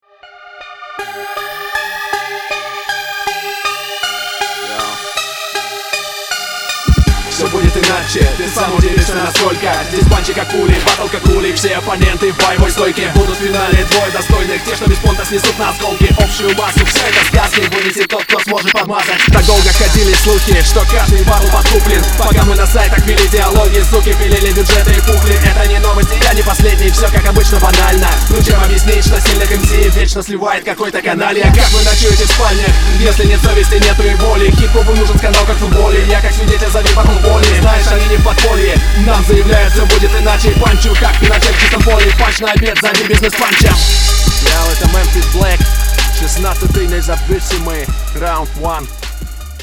Ровно в общем-то читаешь, но речевые обороты всё губят - какули, бизнес-панчи, канальи, лексикон стоит более логично использовать